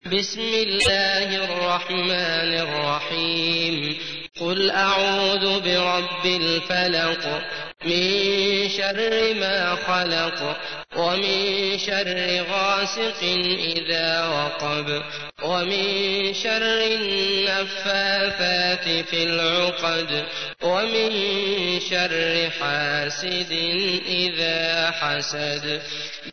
تحميل : 113. سورة الفلق / القارئ عبد الله المطرود / القرآن الكريم / موقع يا حسين